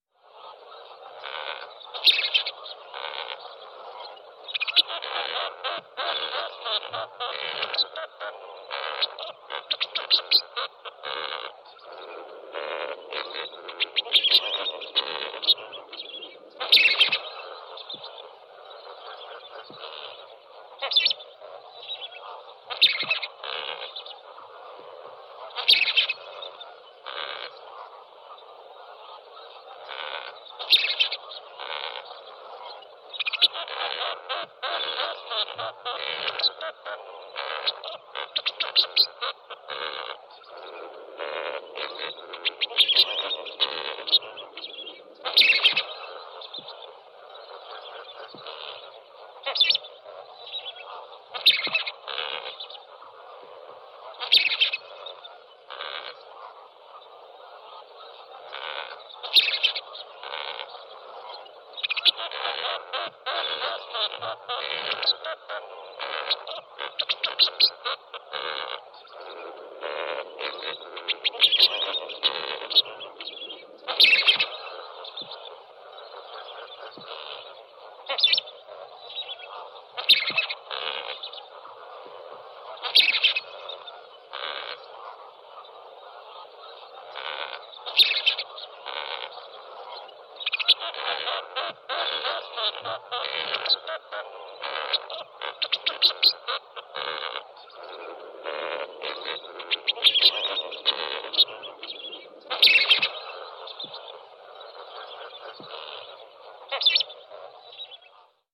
На этой странице собраны звуки фламинго – от нежных перекличек до громких криков.
Звуки фламинго: Атмосферные шумы стаи фламинго